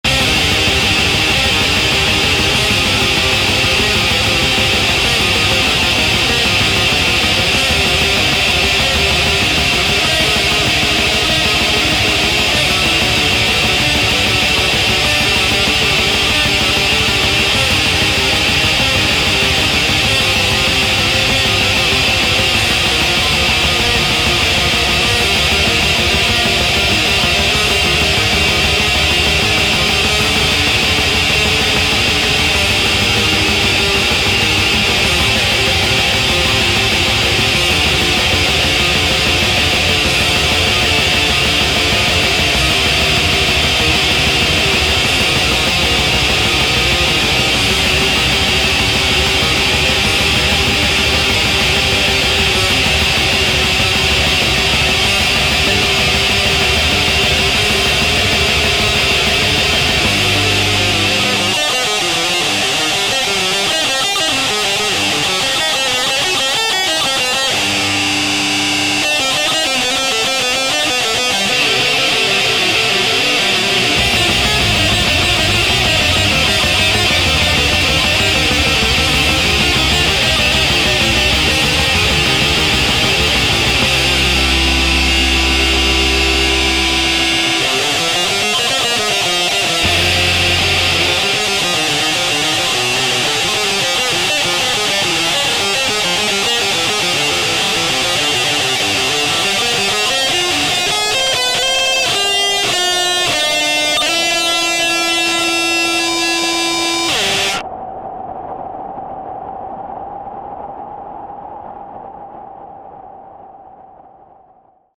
Black Metal transcription